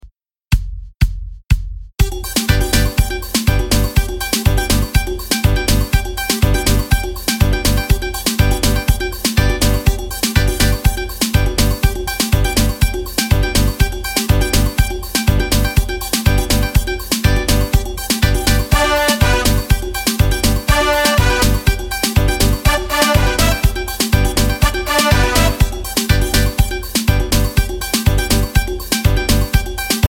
Soca/Calypso